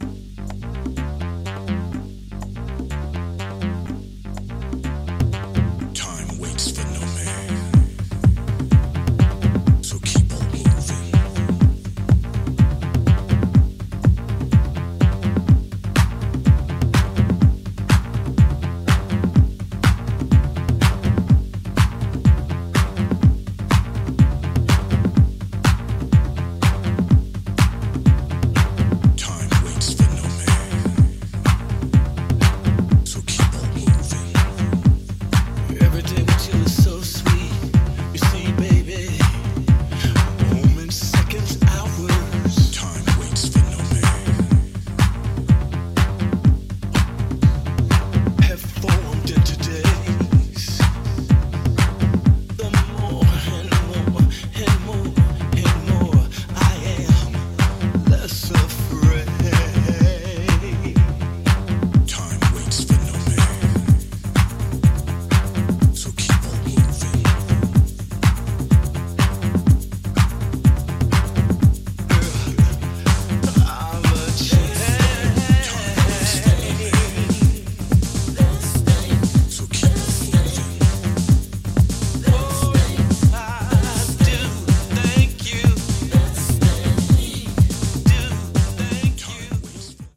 シルキーで研ぎ澄まされたディープ・ハウスを展開しています！
アシッド/シカゴ・ハウスの要素が強まったA-2、美麗なパッドが浸透するB-2のセルフリミックスもお聴き逃しなく。